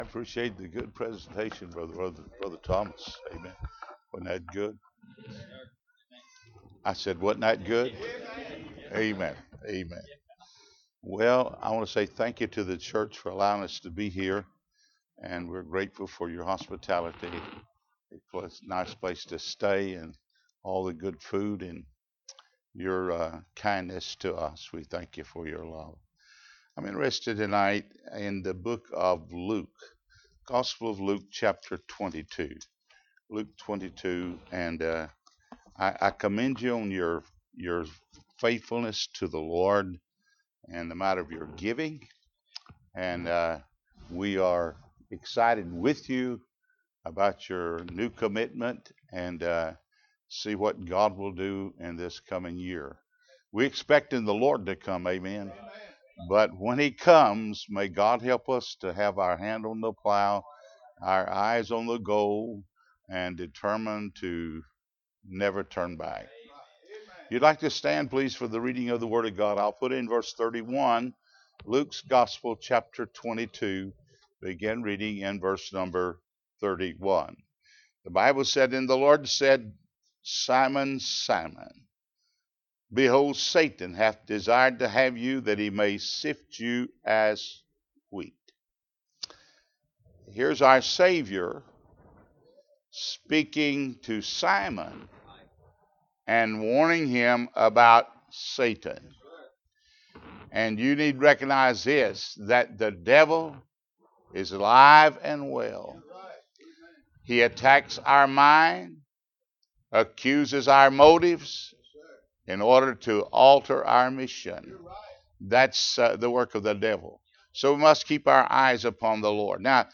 Luke 22:31-46 Service Type: Mission Conference Bible Text